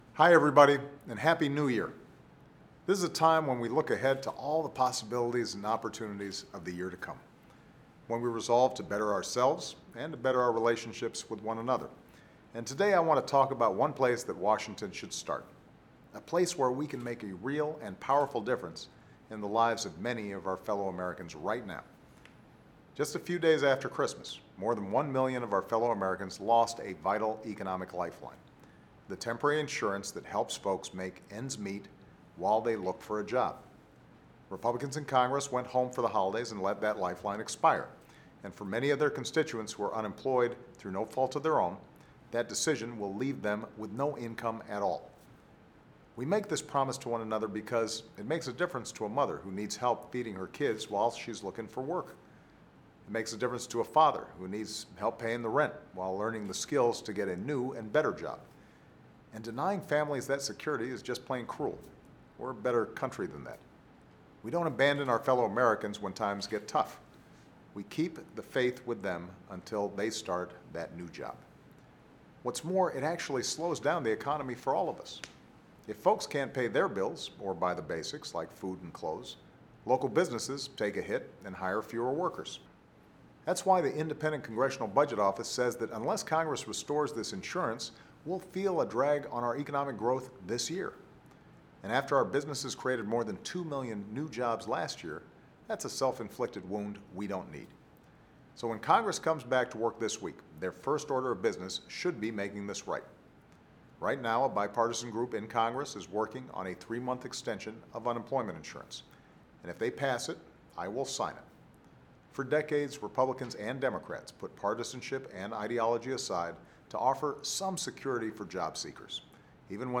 Weekly Address: Time to Pass Bipartisan Legislation to Extend Emergency Unemployment Insurance
In this week’s address, President Obama said Congress should act to extend emergency unemployment insurance for more than one million Americans who have lost this vital economic lifeline while looking for a job.